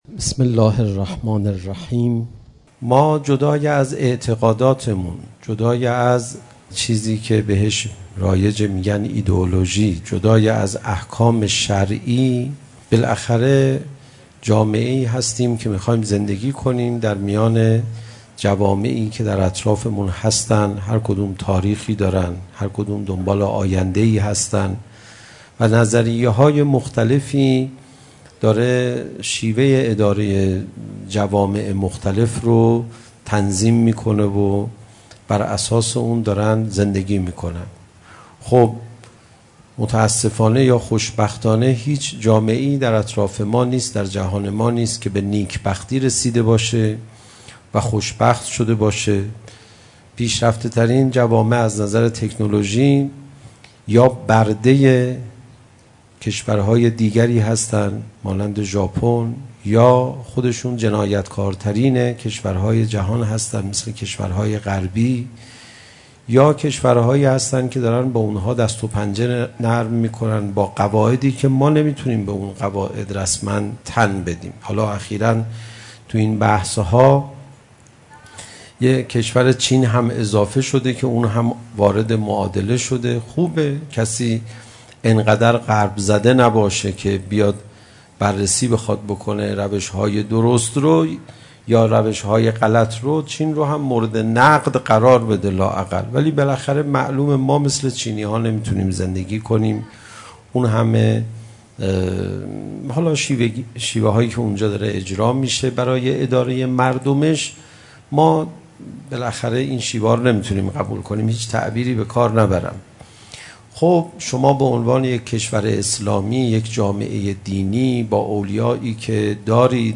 سخنرانی استاد پناهیان | محرم 1401 | موسسه فرهنگی هنری اندیشه شهید آوینی
زمان: محرم 1401 مکان: دانشگاه امام صادق(ع) - هیئت میثاق با شهدا موضوع: مختصات زمان ما و آینده‌ای که تا ظهور در پیش داریم-